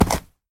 Sound / Minecraft / mob / horse / jump.ogg
jump.ogg